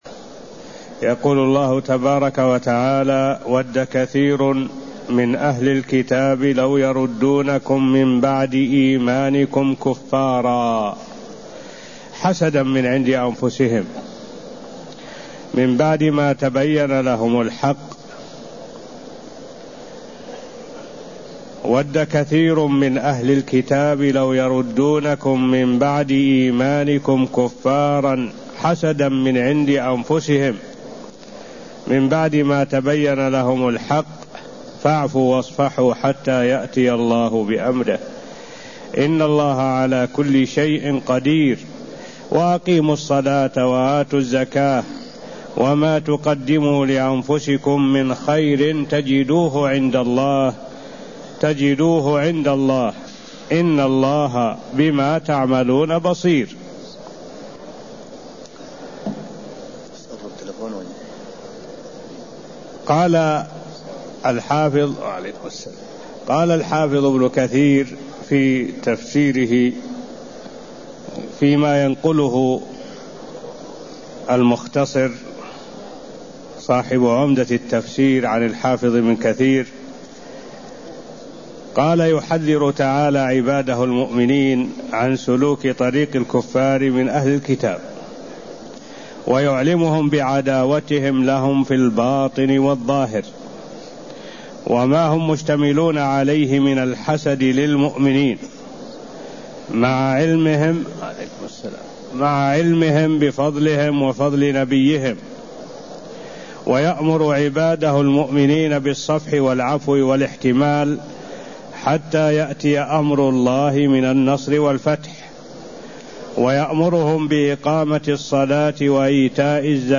المكان: المسجد النبوي الشيخ: معالي الشيخ الدكتور صالح بن عبد الله العبود معالي الشيخ الدكتور صالح بن عبد الله العبود تفسير الآية109ـ110 من سورة البقرة (0061)